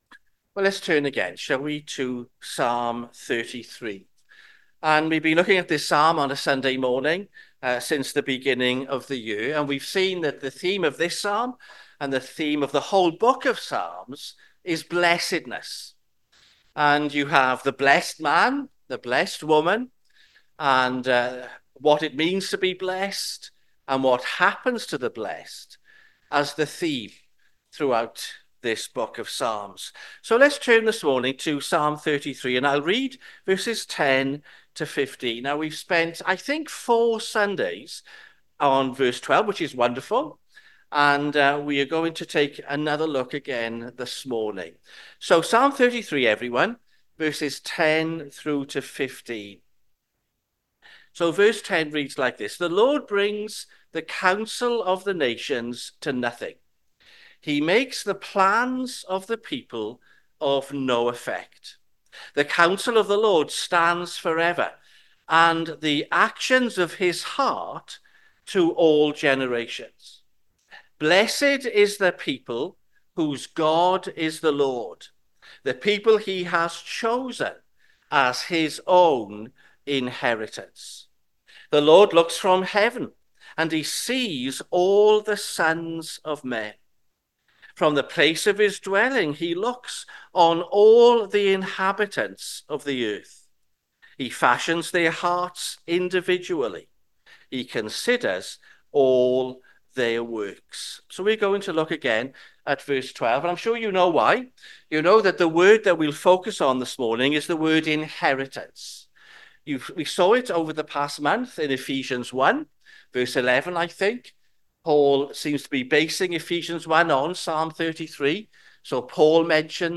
sermon-2025-b-9th-february-am.mp3